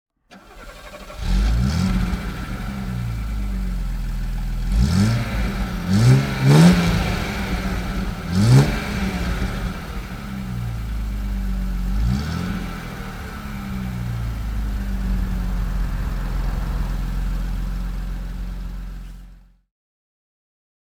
Mercedes-Benz 250 T (1979) - Starten und Leerlauf